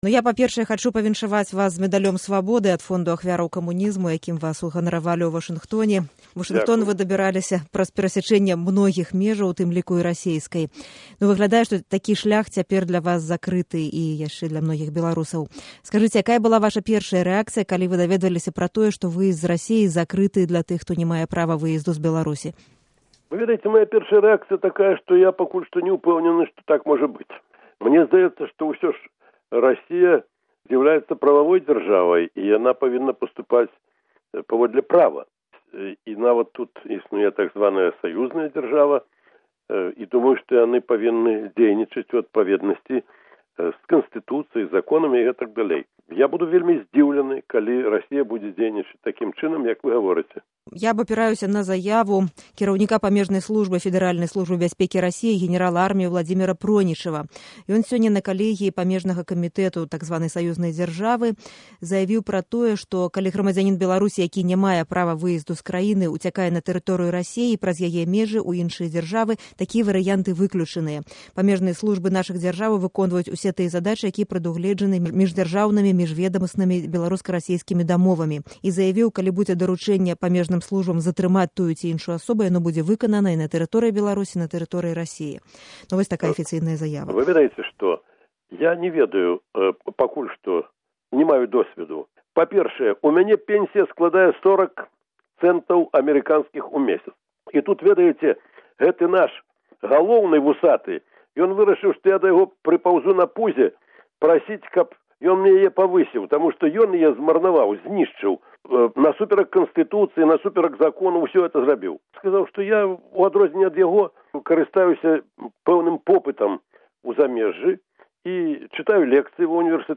На пытаньні Радыё Свабода адказаў Станіслаў Шушкевіч, першы кіраўнік незалежнай Беларусі, сёньня — невыязны.
Гутарка са Станіславам Шушкевічам, 6 красавіка 2012